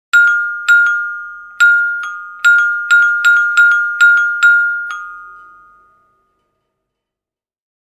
Repeated Ding Dong Doorbell Sound Effect
Description: Repeated ding dong doorbell sound effect. Someone rings the ding dong doorbell several times nervously at the apartment entrance. The repeated chimes create a sense of urgency or impatience, perfect for tense scenes or realistic sound design.
Repeated-ding-dong-doorbell-sound-effect.mp3